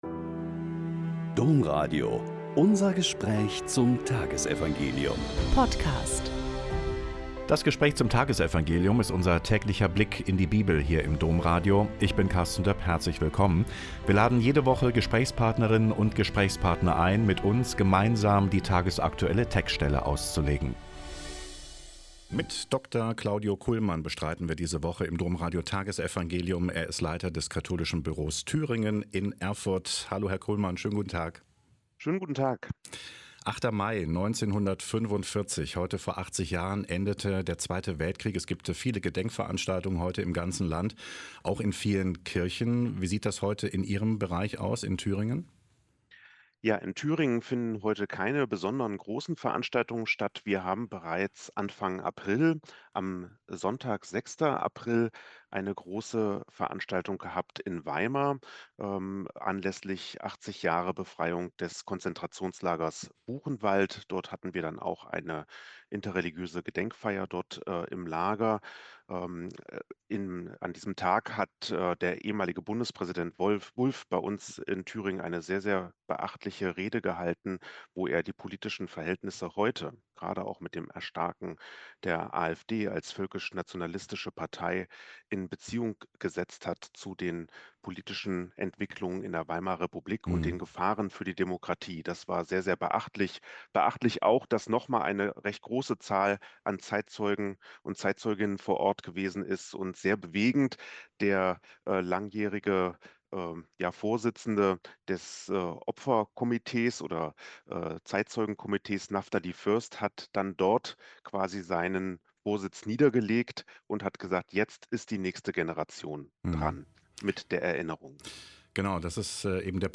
Joh 6,44-51- Gespräch